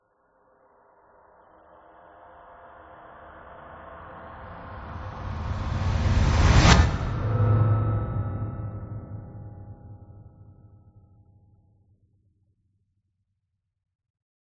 crescendo.mp3